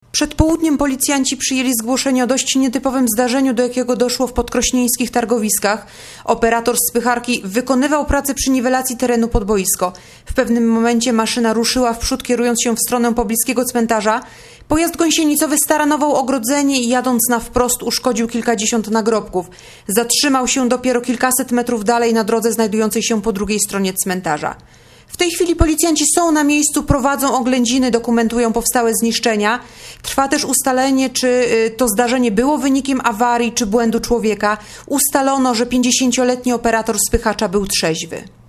Mówi podkom.